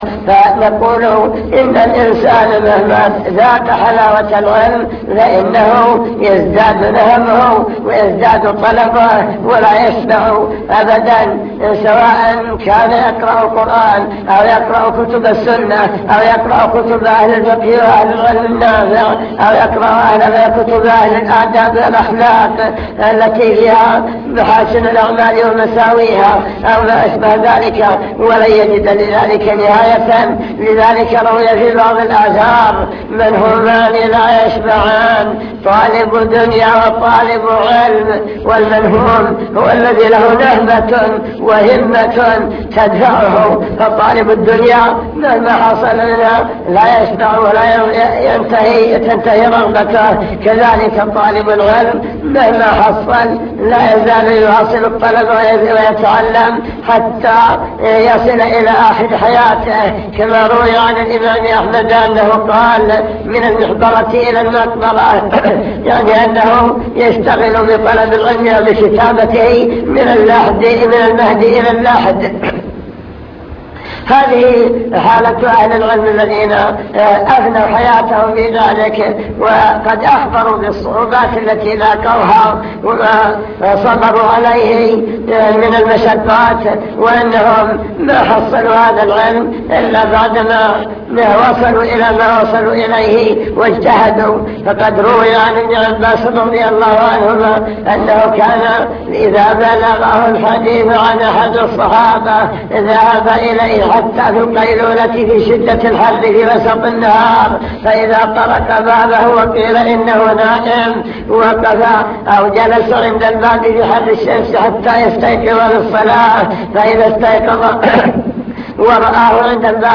المكتبة الصوتية  تسجيلات - محاضرات ودروس  محاضرات عن طلب العلم وفضل العلماء أهمية التفقه في الدين وكيفية تحقيق معنى العبودية